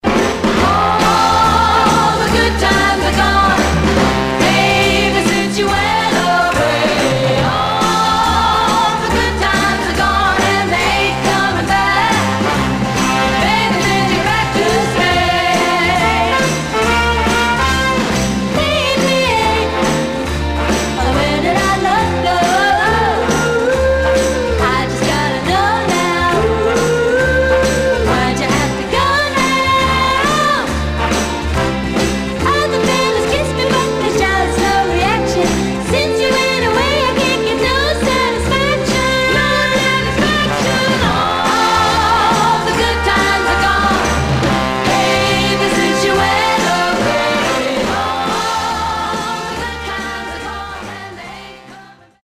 Mono
White Teen Girl Groups